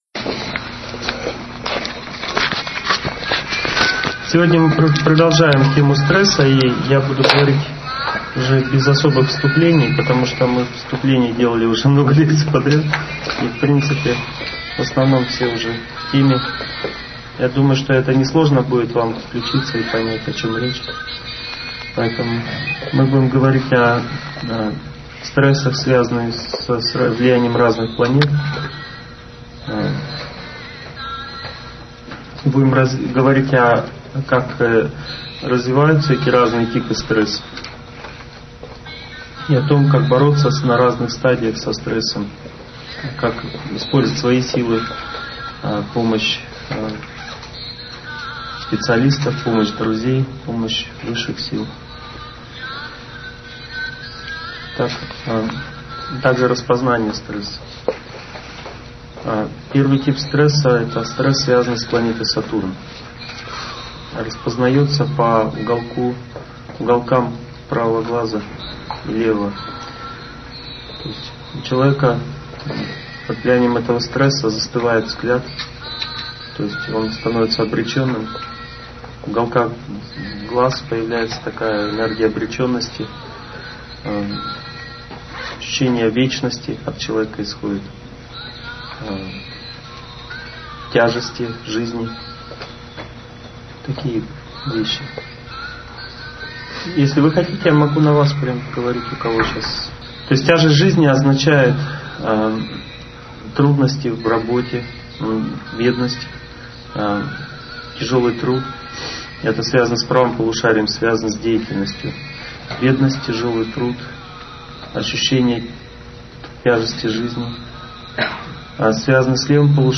Аудиокнига Семейные узы любви. Часть 3 | Библиотека аудиокниг